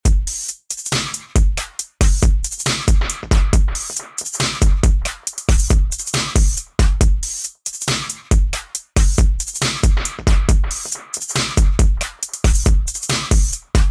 スネアをダブ処理する
一部のスネアパートにディレイをかけることで、ダブ処理を自動化することができます。